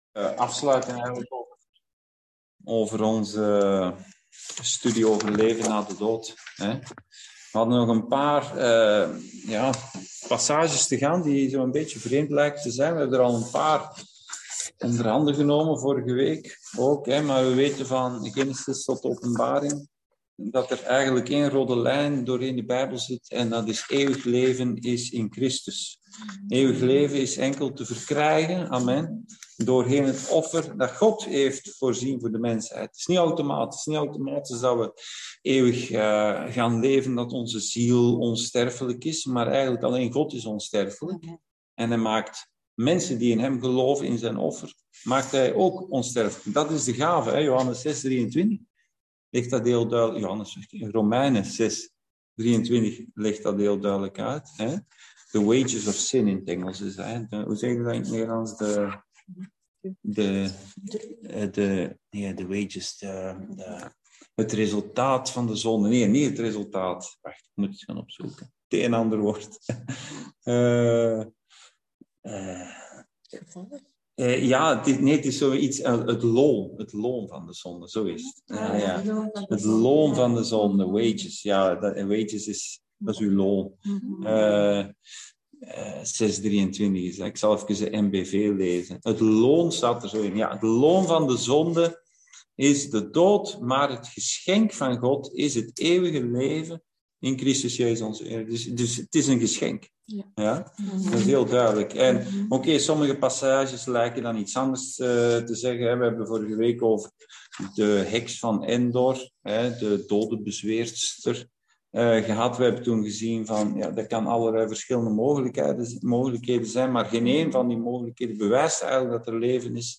Bijbelstudie: leven na de dood: deel 3